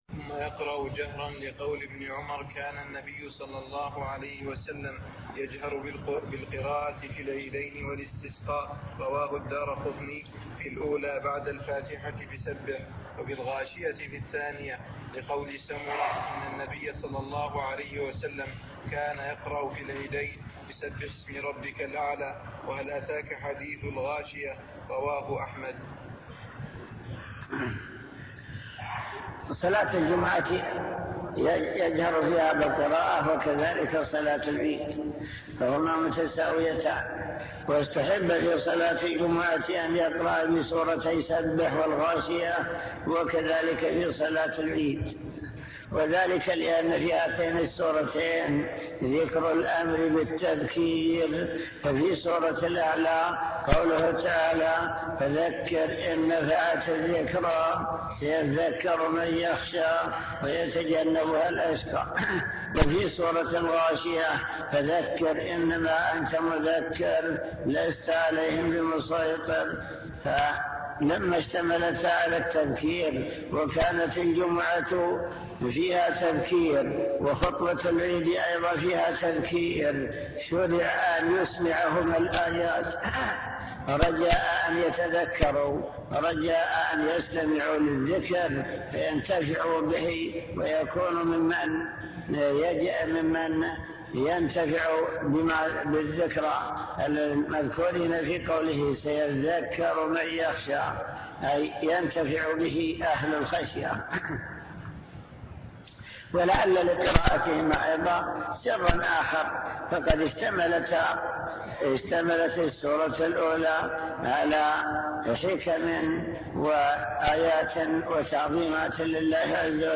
المكتبة الصوتية  تسجيلات - كتب  كتاب الروض المربع الجزء الأول صلاة العيد